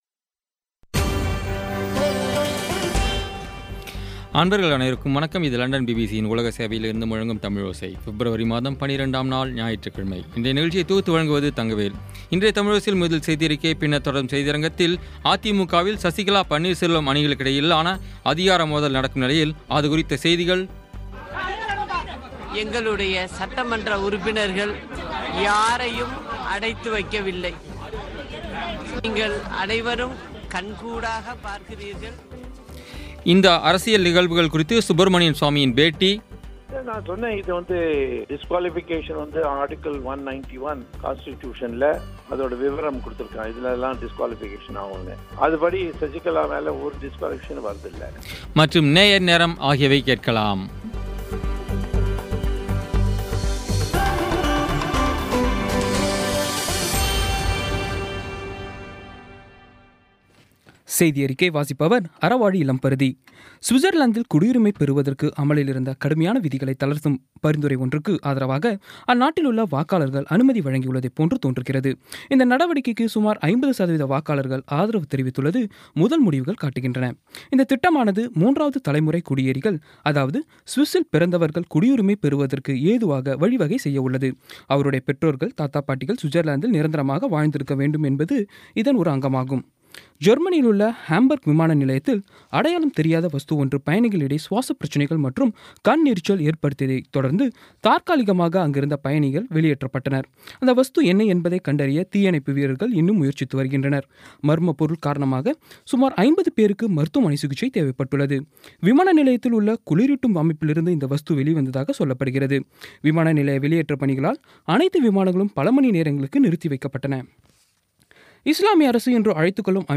இன்றைய தமிழோசையில், அதிமுகவில் சசிகலா – பன்னீர் செல்வம் அணிகளுக்கிடையில் அதிகார மோதல் நடக்கும் நிலையில் அதுகுறித்த செய்திகள்இந்த நிகழ்வுகள் குறித்து சுப்ரமணியன் சுவாமியின் பேட்டிமற்றும் நேயர் நேரம் ஆகியவை கேட்கலாம்.